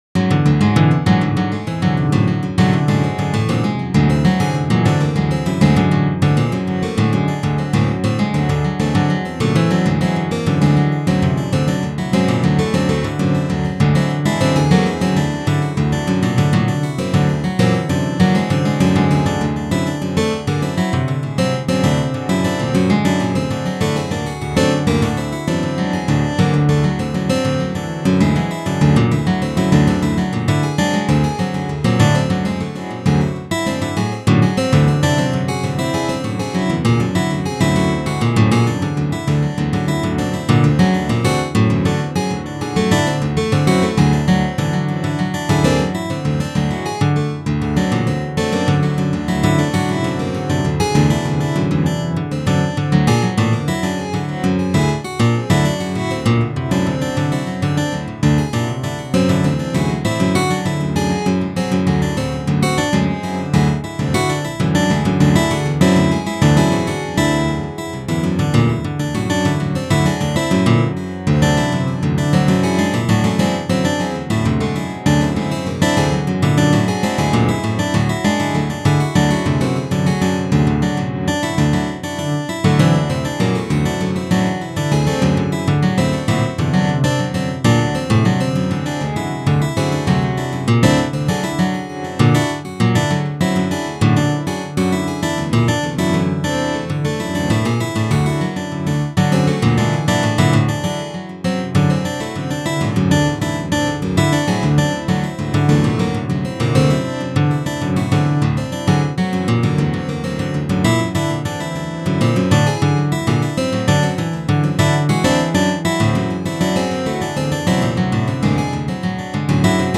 エレクトリック・アコースティック・ギターを使用しての
即興的タッピング演奏の仮想ライブ。
with both hands tapping technique
this is the multi-layered simulated guitars album.